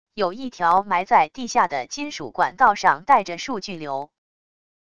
有一条埋在地下的金属管道上带着数据流wav音频